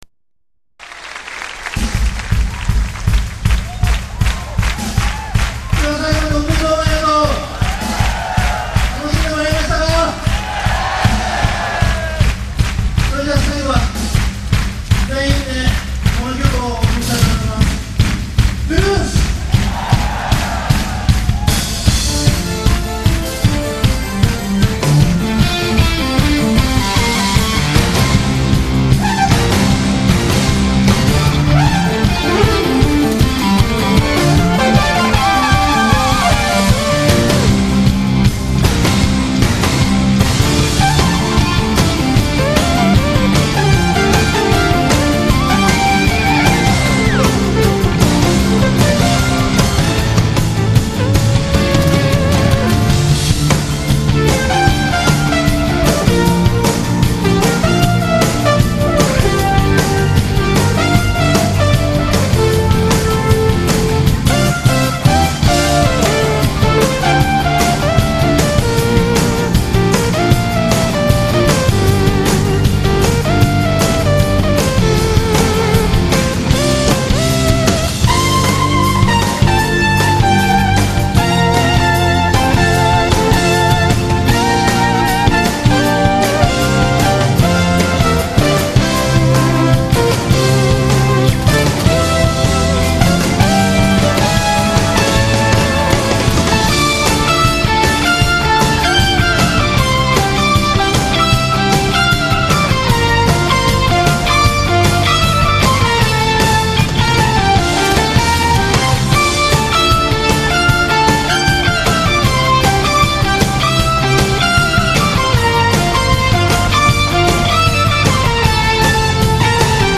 ewi음은 몽롱 그자체!